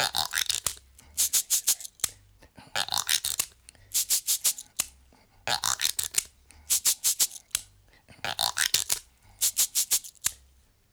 88-PERC-01.wav